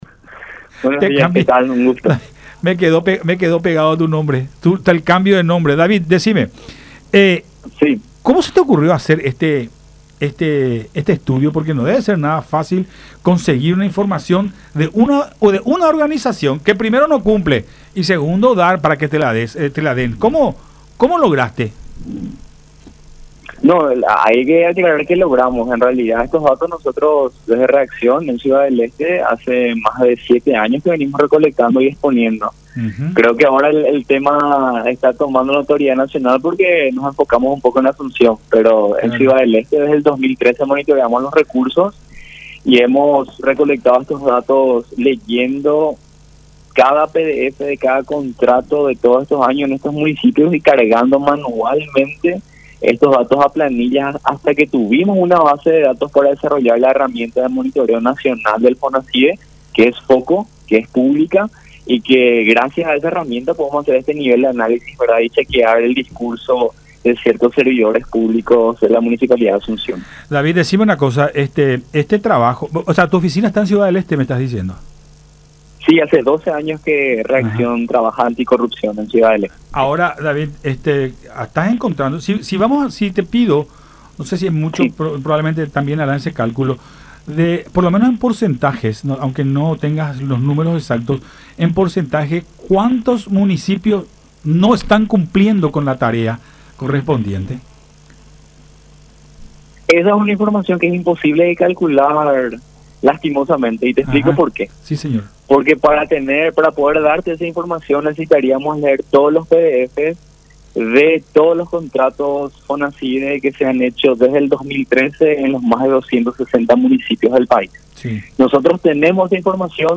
en diálogo con Todas Las Voces por La Unión